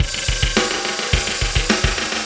Pulsar Beat 29.wav